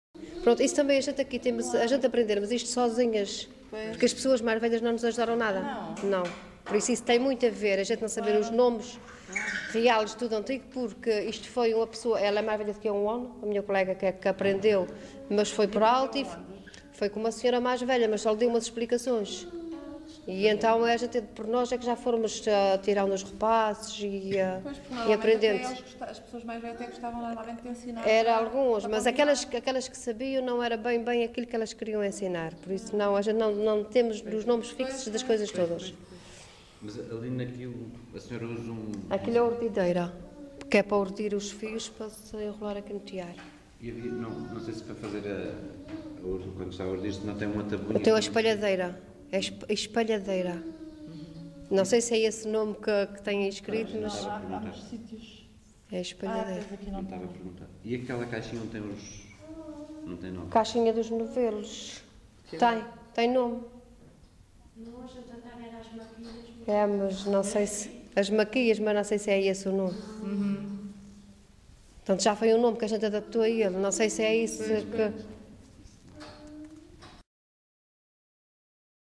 LocalidadeSanto Espírito (Vila do Porto, Ponta Delgada)